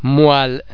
oi oê oe oy [Wa][ typically French sound]
wa_moelle.mp3